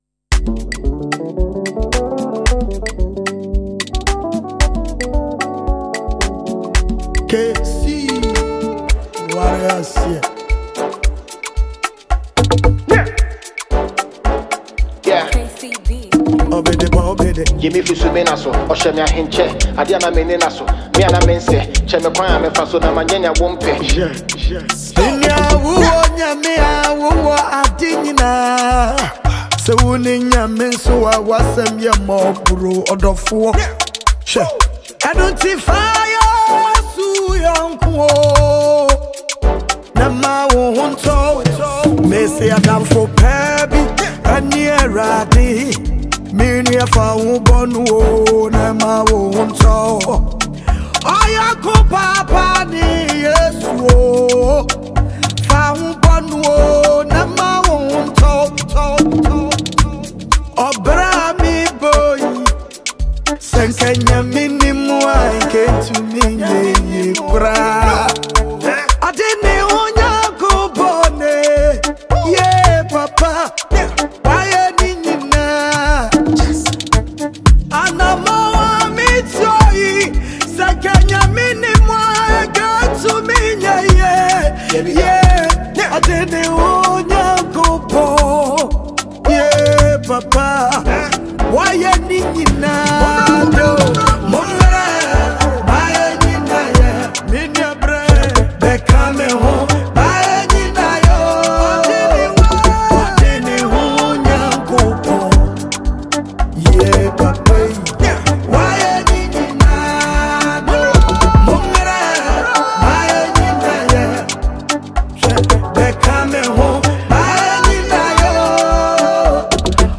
Renowned Ghanaian gospel songstress